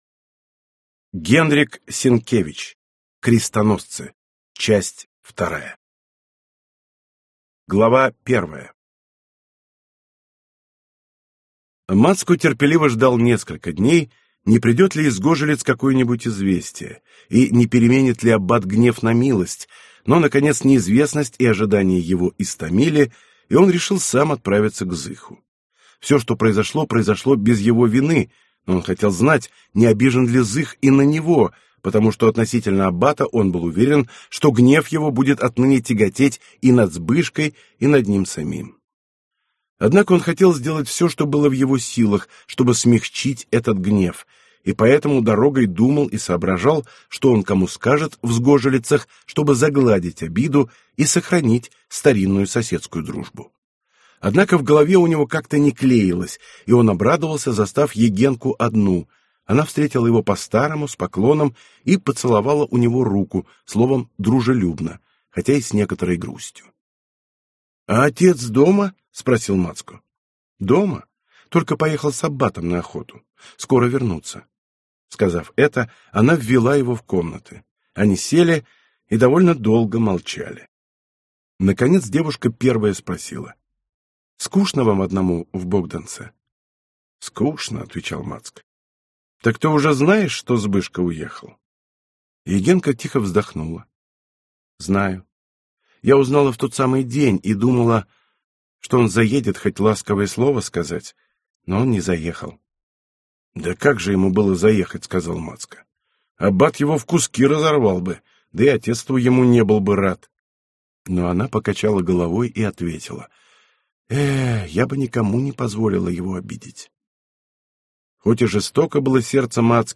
Аудиокнига Крестоносцы. Часть 2 | Библиотека аудиокниг